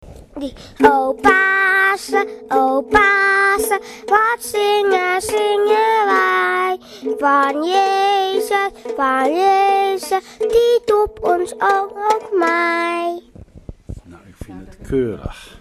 gitaar